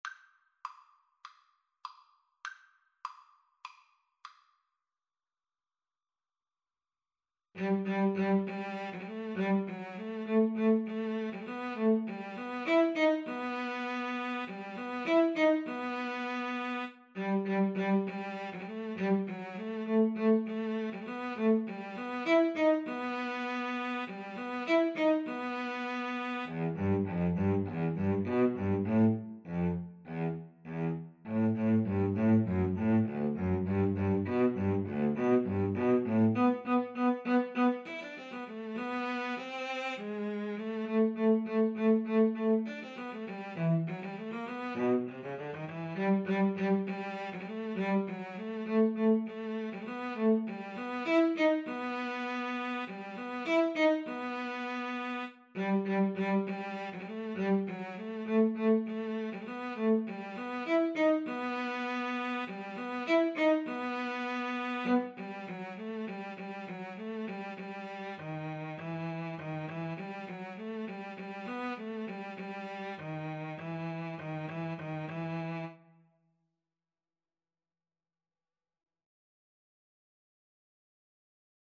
E minor (Sounding Pitch) (View more E minor Music for Cello Duet )
Allegro Moderato (View more music marked Allegro)
Cello Duet  (View more Easy Cello Duet Music)